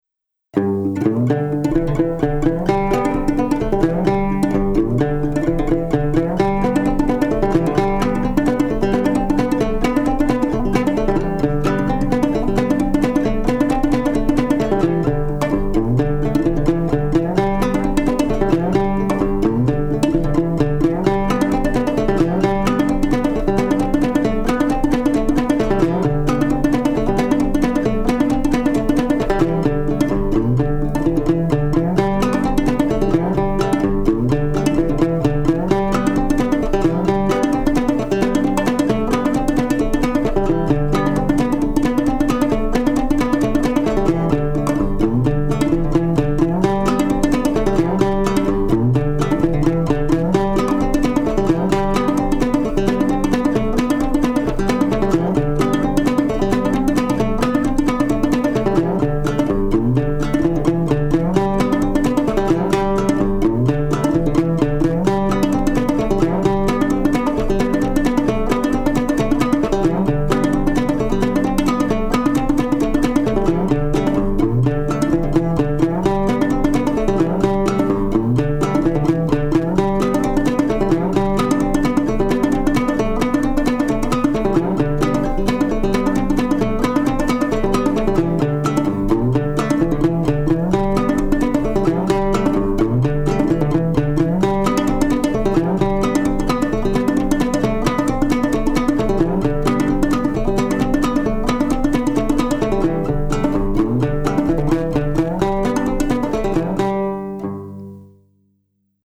Gourd Banjo